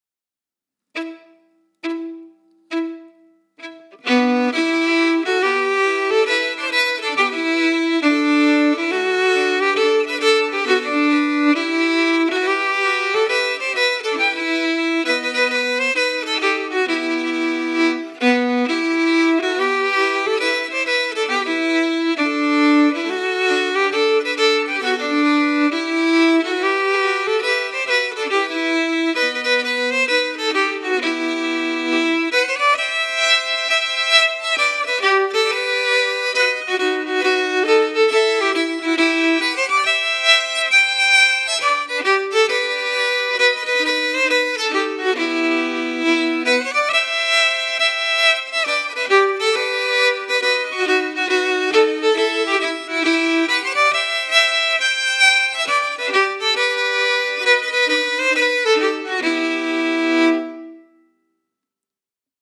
Key: Em
Form: Hornpipe
MP3: (Melody emphasis)